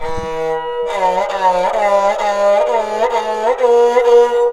SARANGHI2 -L.wav